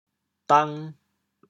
“董”字用潮州话怎么说？
dang2.mp3